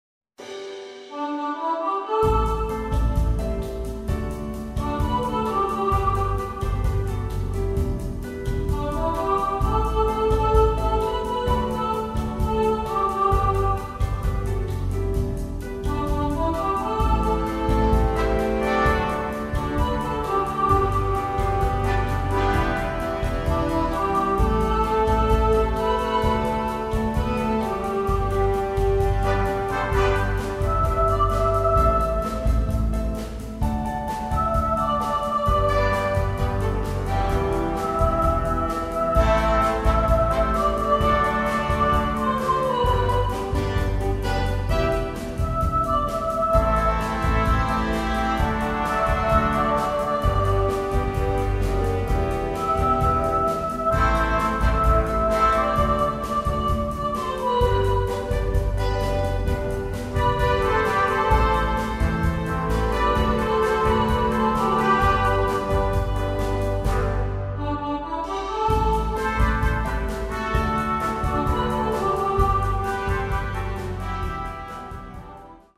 Gattung: Big Band
Disco-Fox